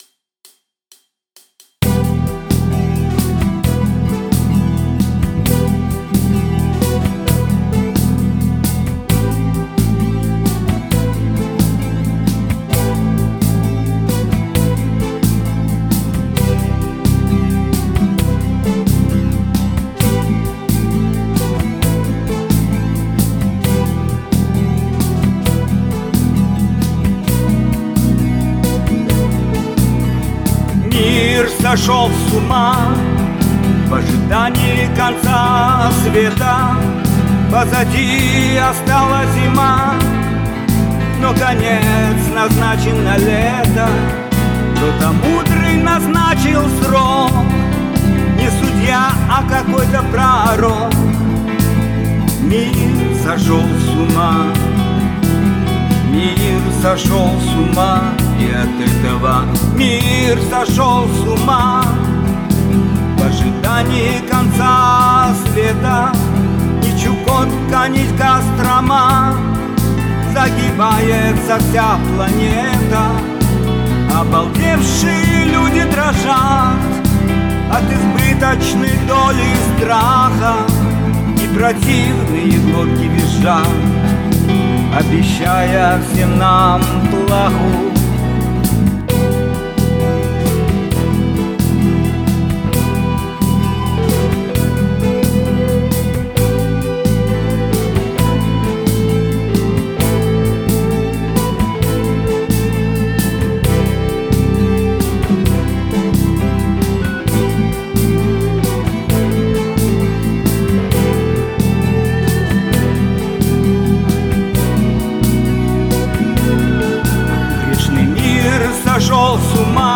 Юмористическая песня
гитара
Юмористическую песню АВРАЛ